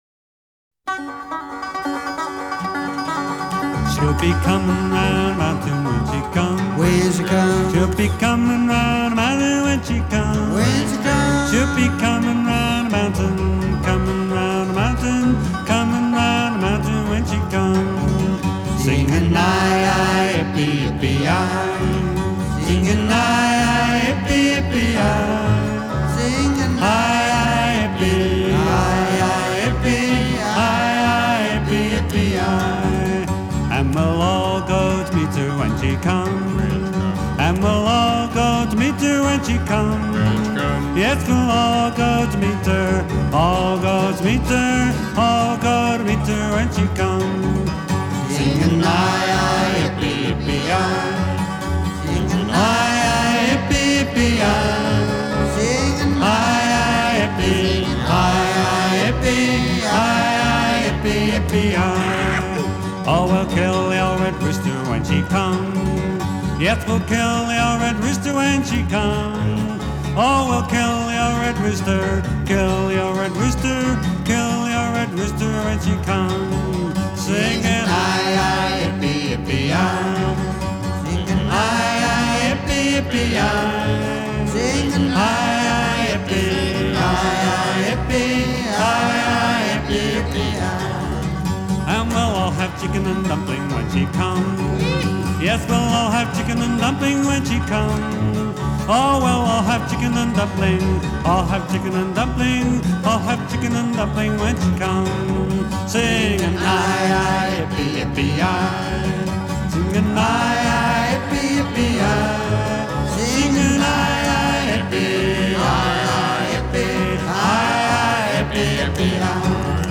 【美國西部牛仔】